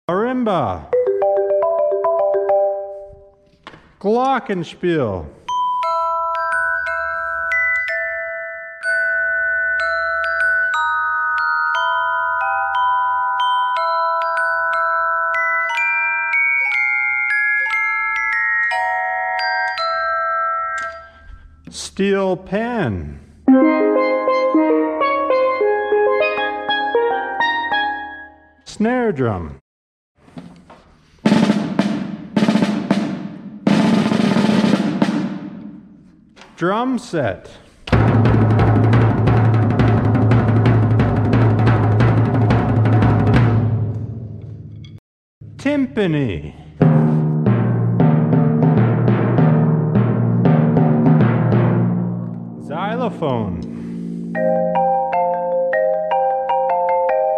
Percussion Instruments